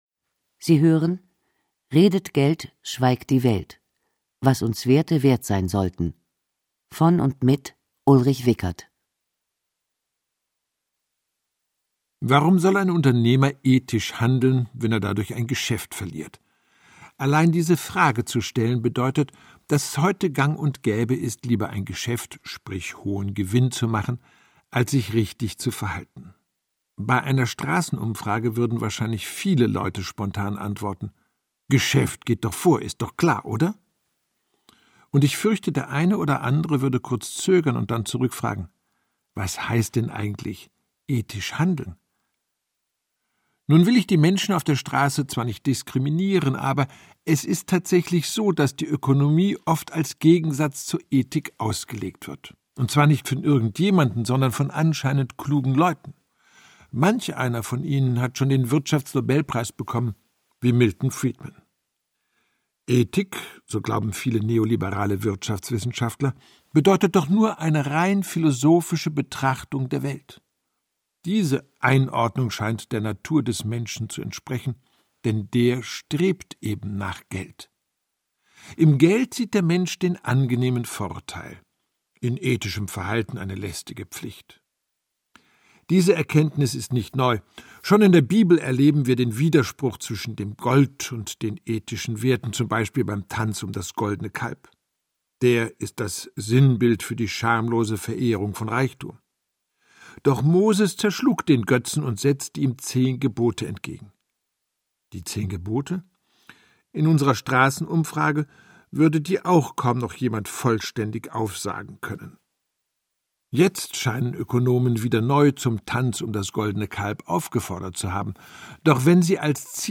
Ulrich Wickert (Sprecher)